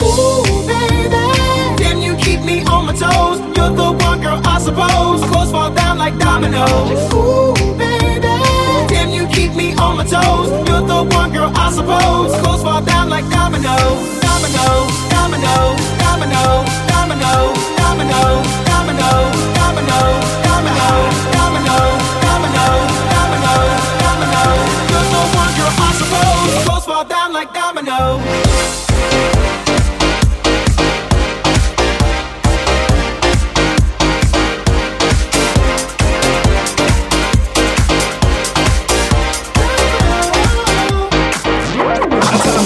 Genere: deep house, tropical, house, club, edm, remix